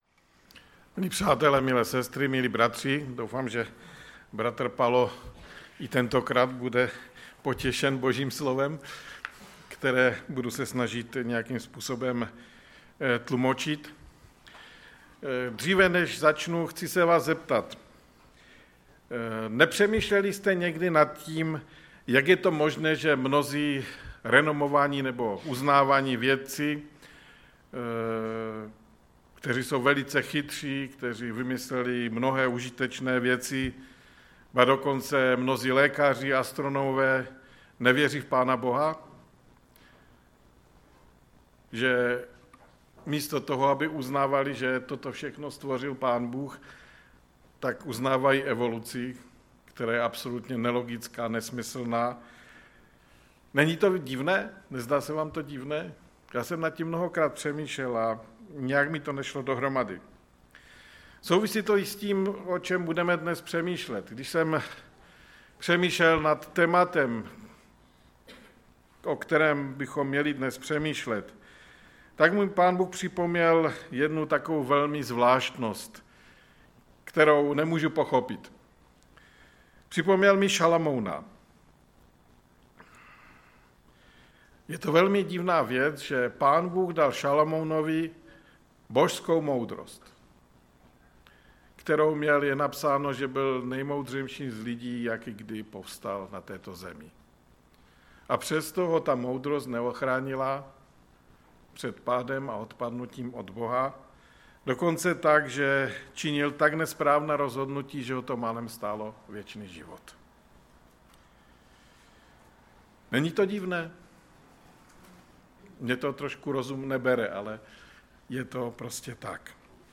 ve sboře Ostrava-Radvanice.
Kázání